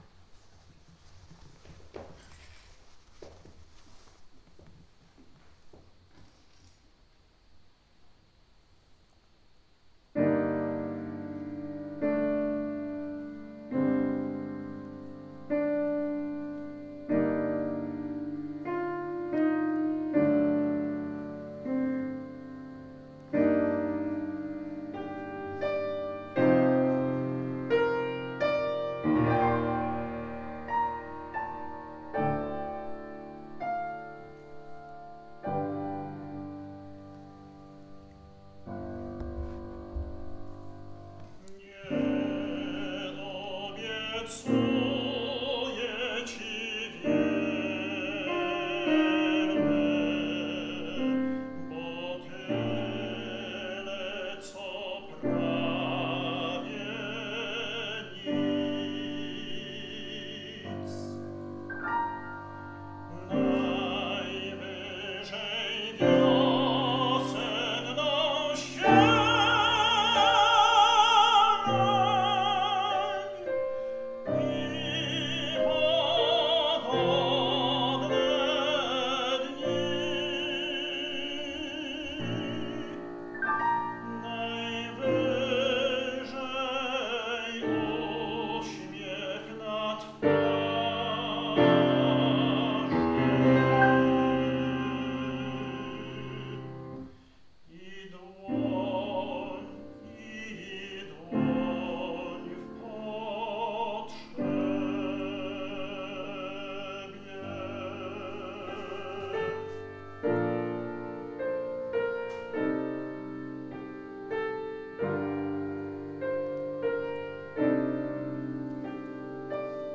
pieśń na głos solowy z fortepianem
na głos i fortepian
tenor
odbyło się w prestiżowej sali koncertowej Kirmser Hall, Kansas State University w Manhattan (USA)
Pieśń Nie obiecuję ci prawie nic jest wysoce ekspresyjna, z zachowaniem centrów tonalnych, podkreśla semantykę liryki miłosnej Bolesława Leśmiana.